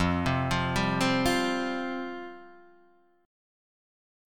Fsus2 chord {1 3 3 0 1 1} chord